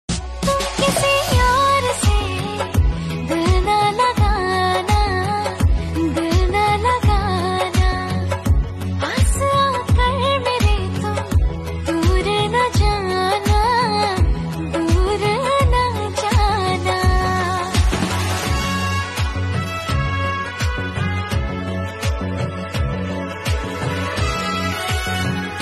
Hindi Songs
• Simple and Lofi sound
• Crisp and clear sound